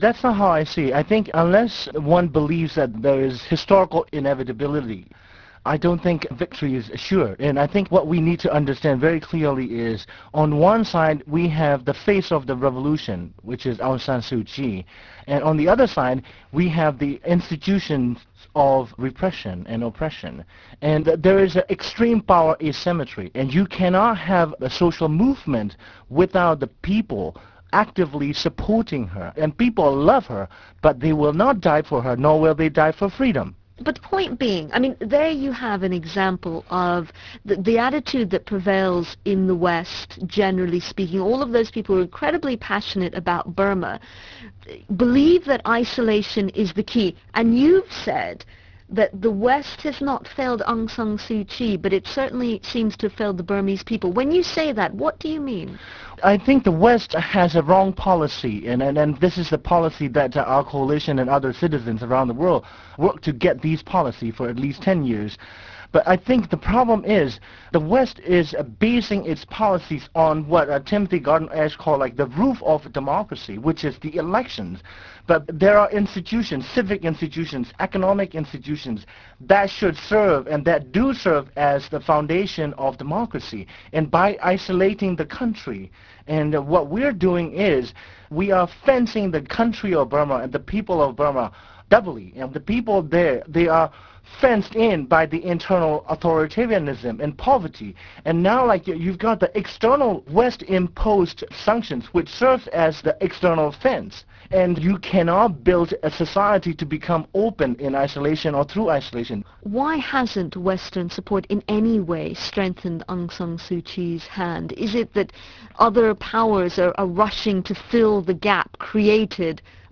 BBC World Service Interview
BBCworldserviceinterview.wav